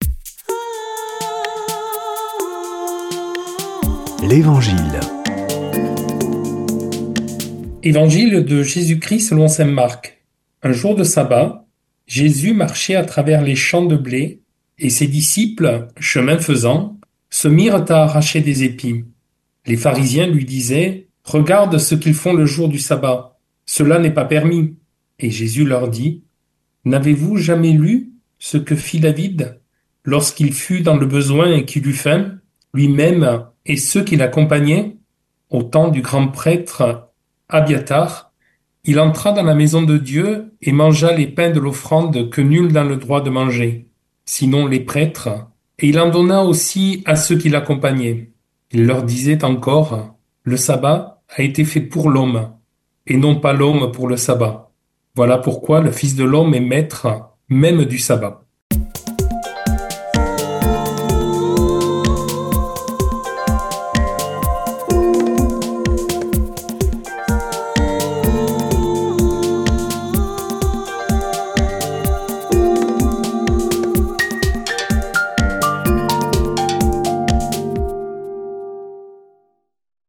Des prêtres de la région